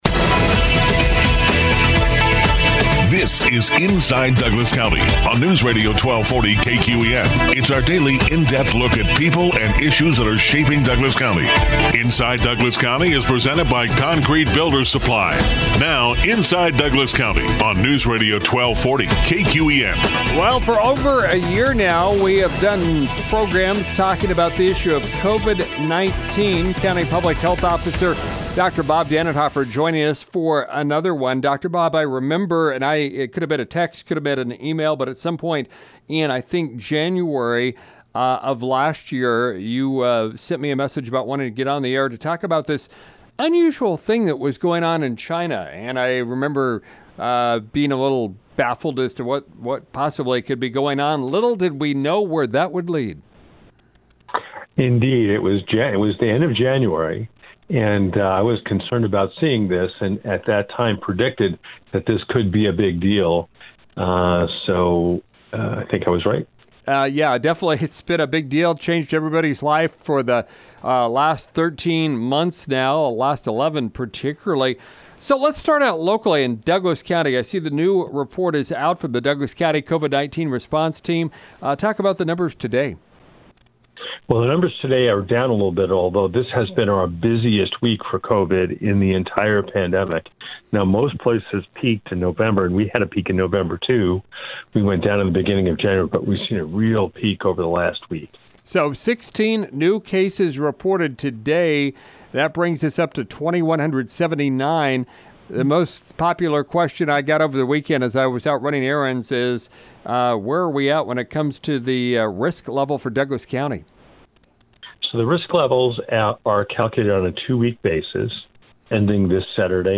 County Public Health Officer Dr. Bob Dannenhoffer with our weekly in-depth look at the COVID-19 situation.